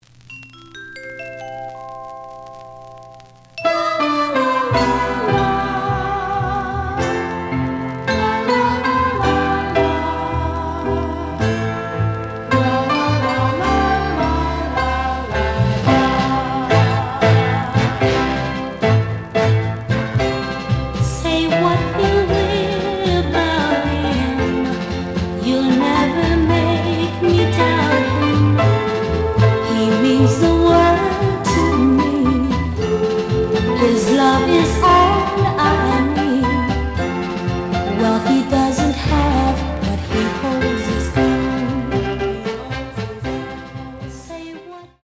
The audio sample is recorded from the actual item.
●Genre: Soul, 60's Soul
Slight edge warp. But doesn't affect playing. Plays good.)